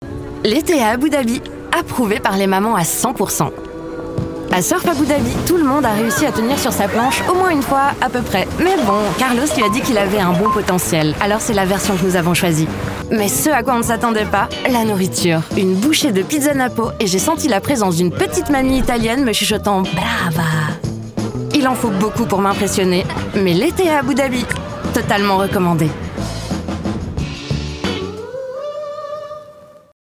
Artiste inspirée, attentive, disponible et polyvalente, avec une voix grave-médium.
Cabine DEMVOX, micro Neumann TLM 103, Scarlett 4i4 et ProTools Studio sur un Mac M1